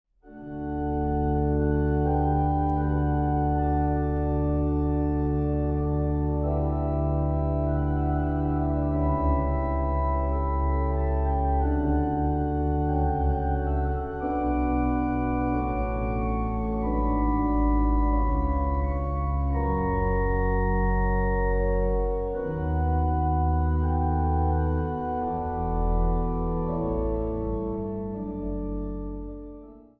Zang | Mannenkoor